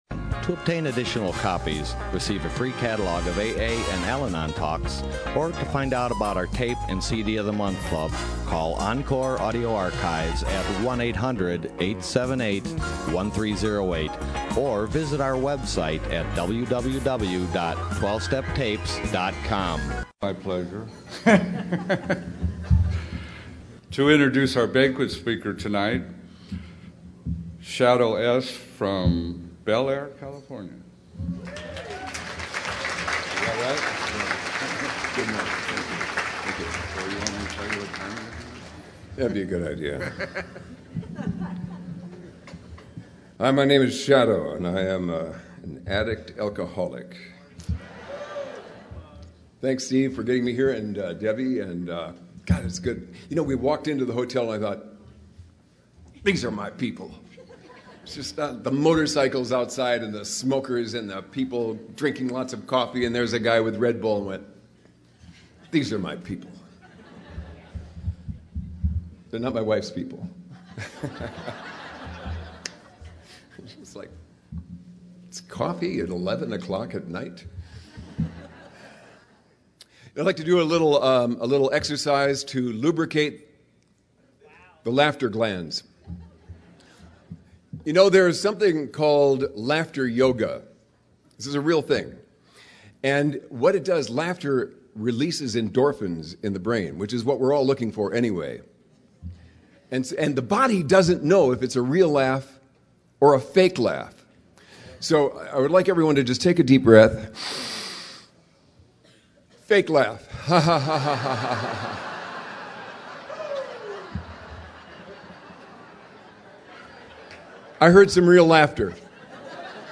Orange County AA Convention 2015